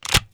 GunSound.wav